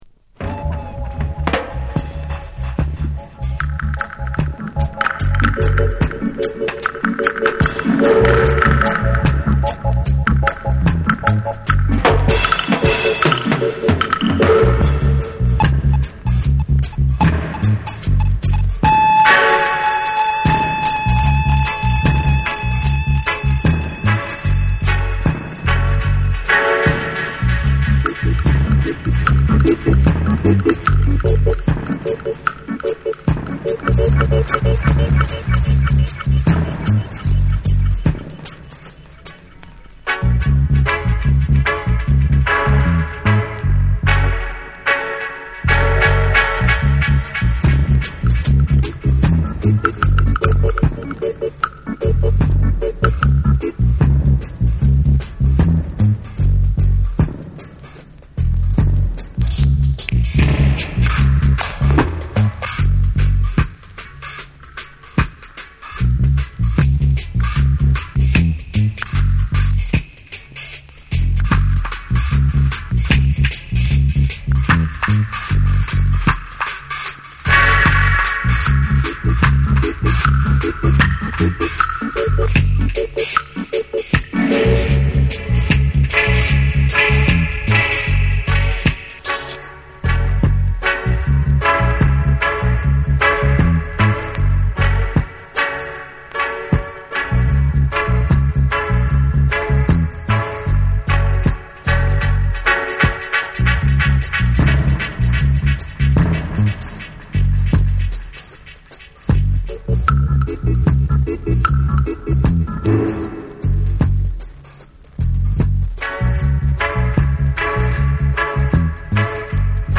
B面のズブズブなダブもカッコ良い！
REGGAE & DUB